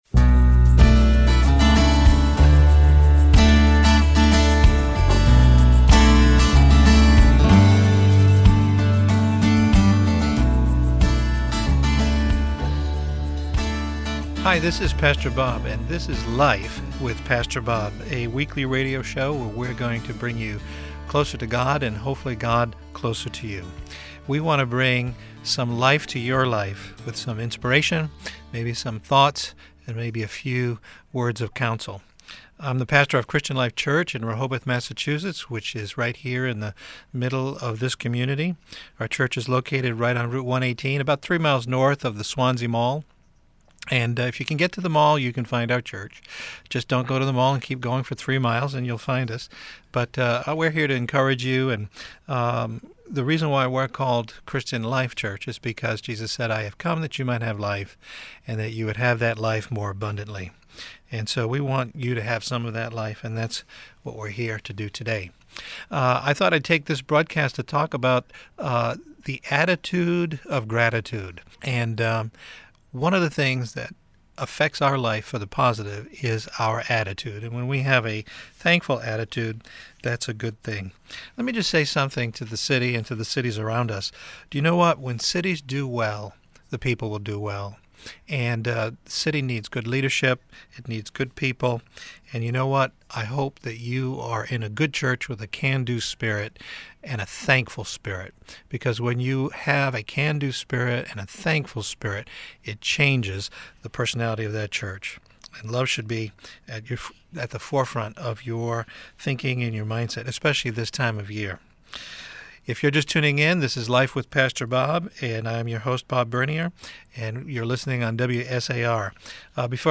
Recorded at WSAR 1480 AM in Somerset, Massachusetts.